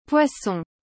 Como pronunciar poisson corretamente?
O “oi” soa como “pua” e o “on” tem um som meio anasalado, tipo “põ”.